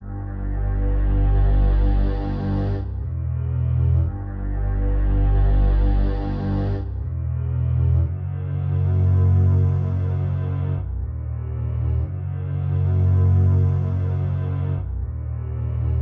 描述：氛围，从外部空间的凉爽垫环... :)
Tag: 75 bpm Ambient Loops Pad Loops 1.08 MB wav Key : A